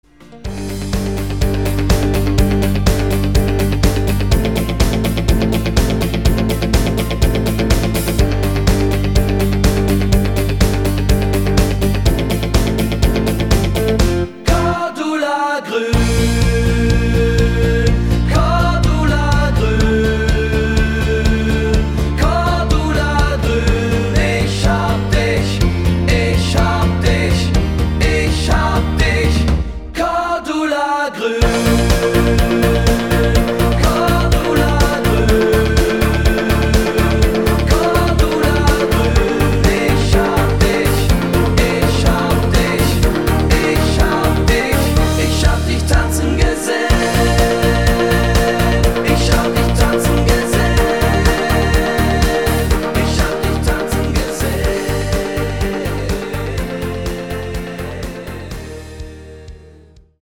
Party Rockversion